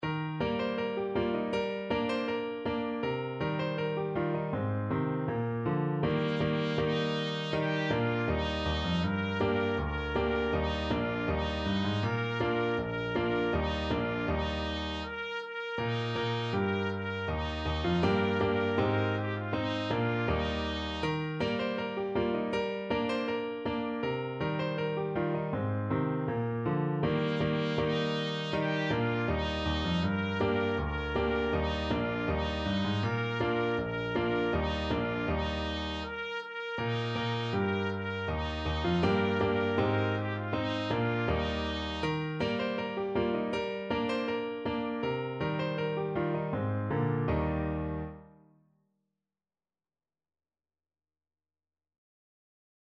Steadily =c.80
2/4 (View more 2/4 Music)
Chinese